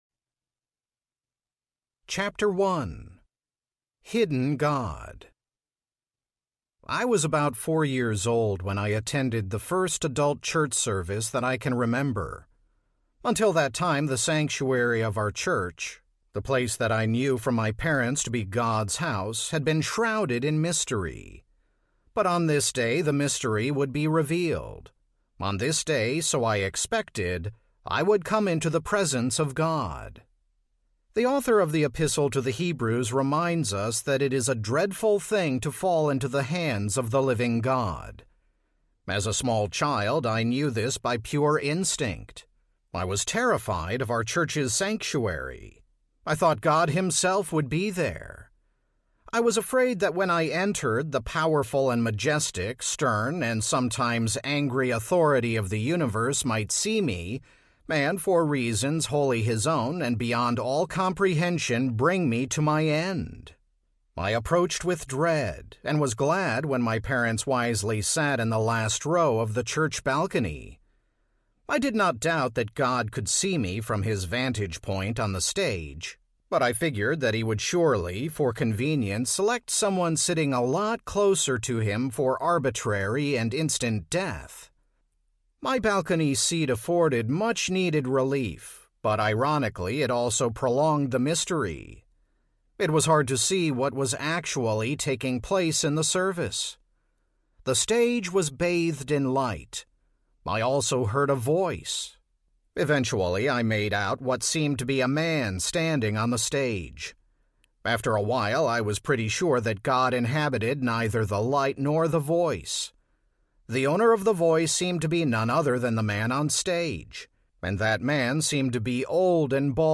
The Hiddenness of God Audiobook
Narrator
7.5 Hrs. – Unabridged